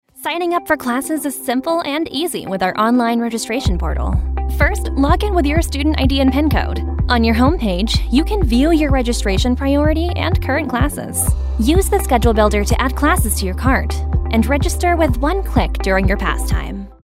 English (American)
Distinctive, Cool, Versatile, Reliable, Friendly
E-learning